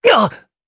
Esker-Vox_Damage_jp_03.wav